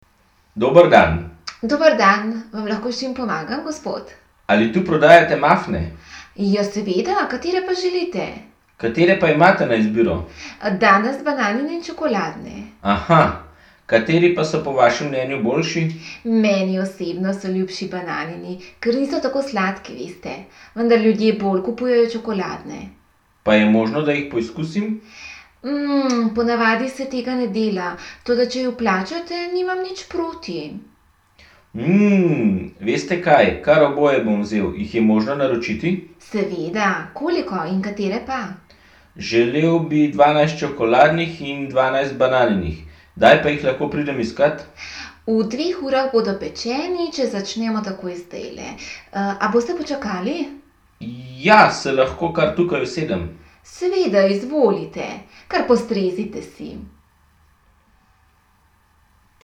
Poslušaj tri pogovore, ki se odvijajo v slaščičarni, in bodi pozoren na to, v katerem pogovoru se bolj prepričuje, v katerem se bolj raziskuje in v katerem bolj pogaja.
Pogovor 1
V prvem pogovoru; gospod sprašuje po vrstah in okusu tort.